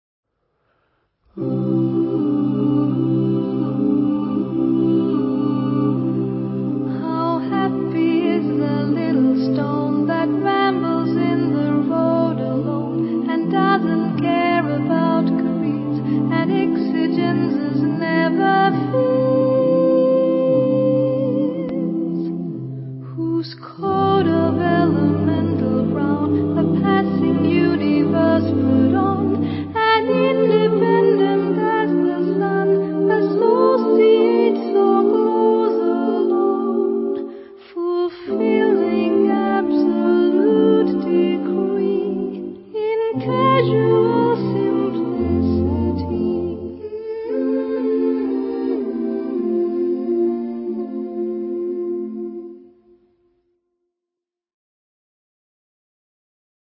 Vocal jazz